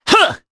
Kasel-Vox_Attack1_jp.wav